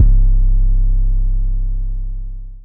spinz 808 (distortion).wav